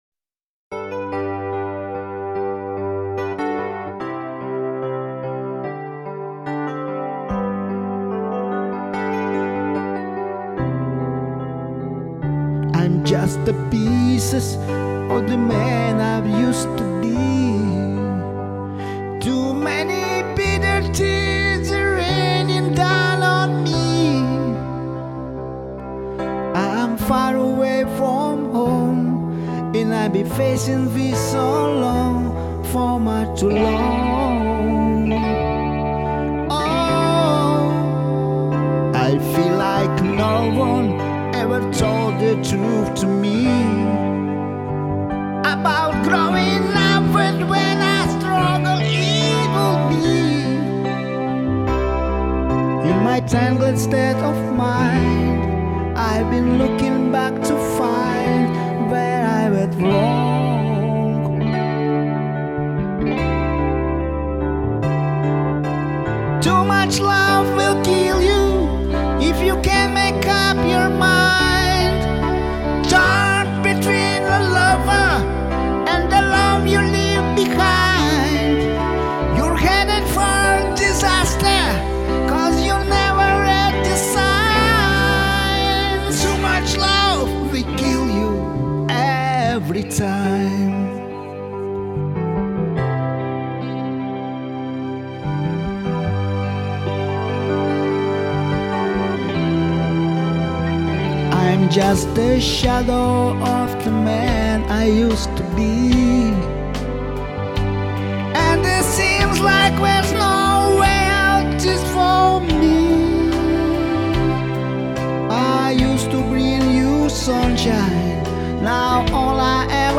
что мешало моим ушам - "форсированный" голос..